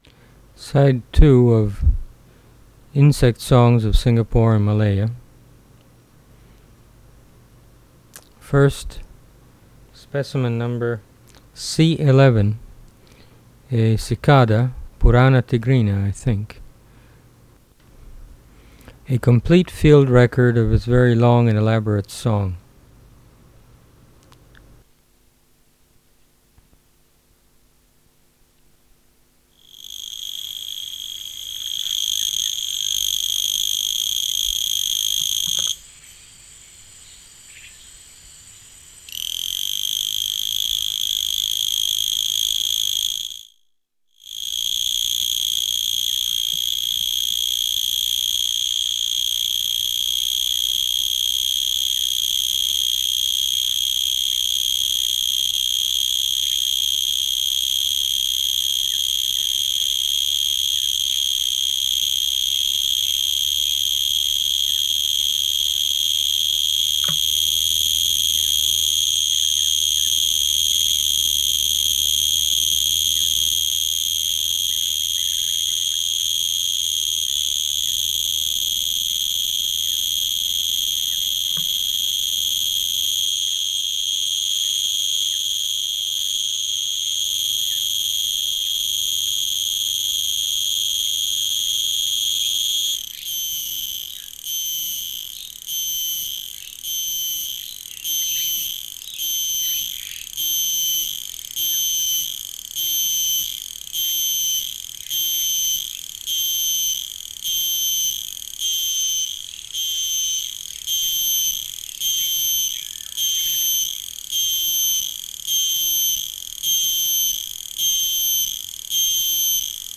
Species: Purana tigrina
Recording Location: Asia: Singapore